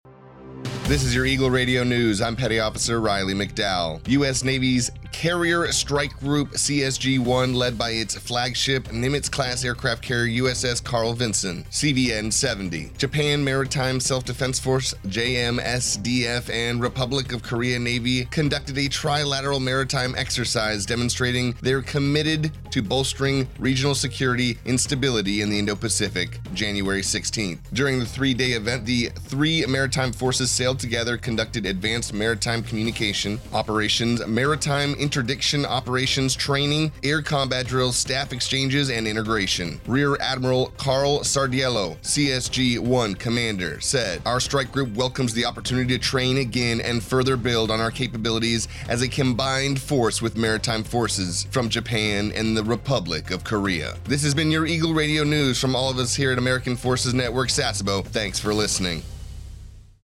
A TFNewscast for AFN Sasebo's radio about the U.S. Navy’s Carrier Strike Group (CSG) 1, led by its flagship, Nimitz-class aircraft carrier USS Carl Vinson (CVN 70), Japan Maritime Self-Defense Force (JMSDF) and Republic of Korea Navy (ROKN), conducting a trilateral maritime exercise, demonstrating their commitment to bolstering regional security and stability in the Indo-Pacific, January 16, 2024.